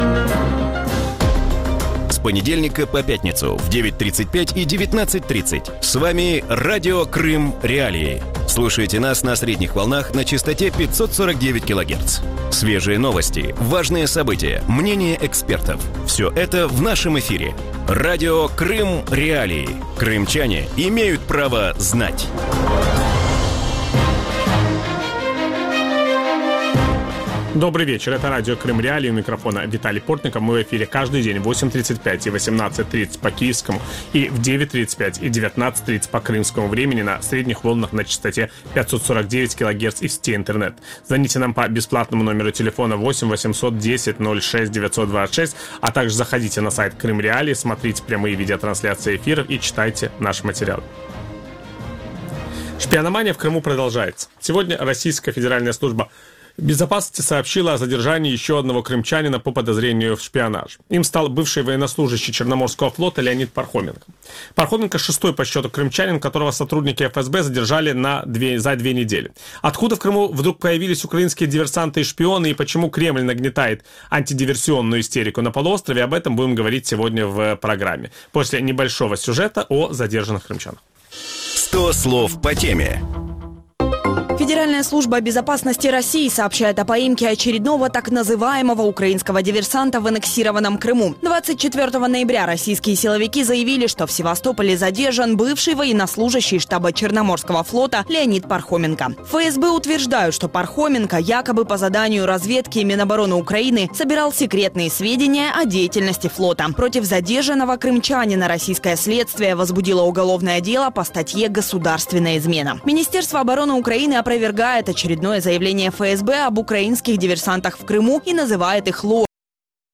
У вечірньому ефірі Радіо Крим.Реалії говорять про сплеск арештів кримчан, яких підозрюють у роботі на українські спецслужби. Чому «диверсантів» почали заарештовувати через 2,5 роки після анексії, як впливає активність спецслужб на імідж півострова і як атмосфера небезпеки може вплинути на півострів? На ці питання відповість російський журналіст Олександр Подрабінек. Ведучий: Віталій Портников.